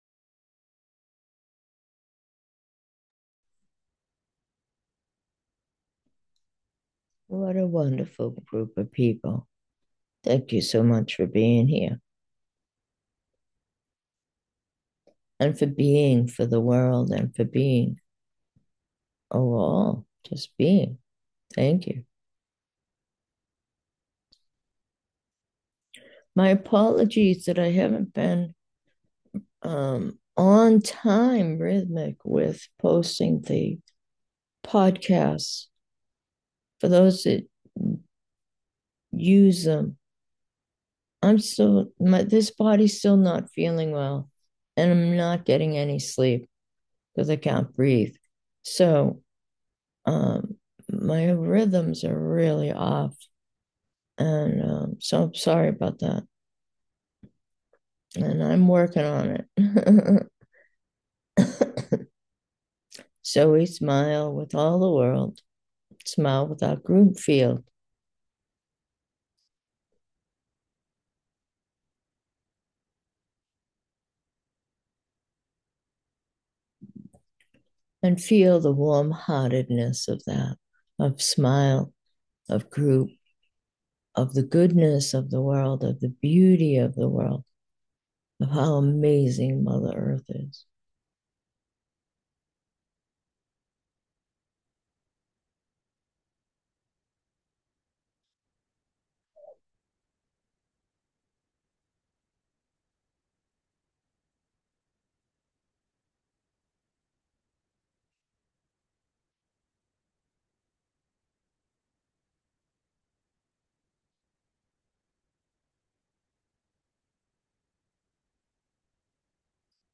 Meditation: putting it together 3
Gentle.